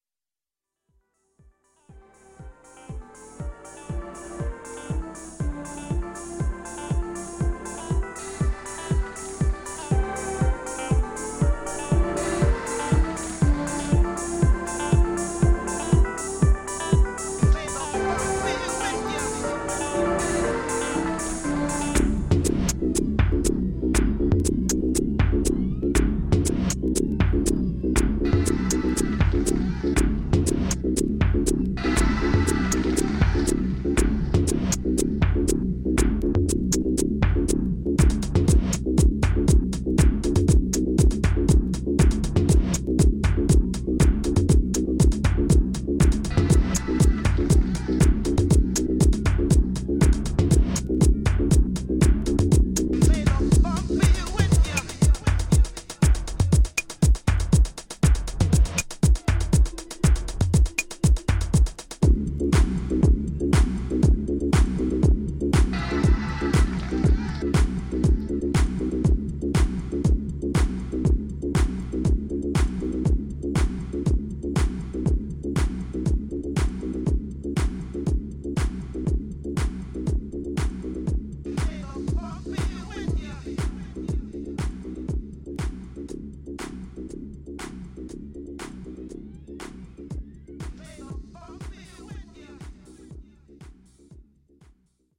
classic warehouse theme
almost anthemic, synth laden Chicago jack release